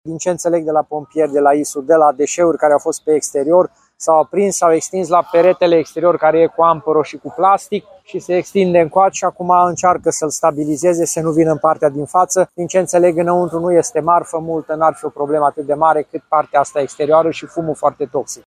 Primarul Călin Bibarț a venit la fața locului și spune că, din informațiile primite de la pompieri, în interior nu era multă marfă.